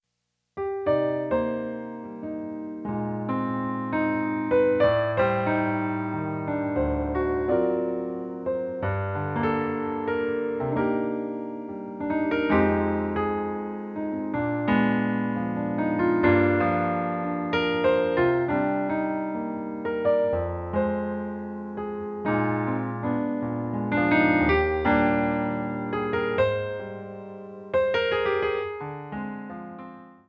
Piano Stylings